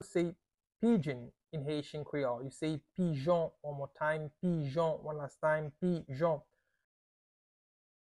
Pronunciation:
21.How-to-say-Pigeon-in-Haitian-Creole-Pijon-pronunciation-by-a-Haitian-teacher.mp3